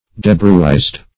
Debruised \De*bruised"\, a. [Cf. OF. debruisier to shatter,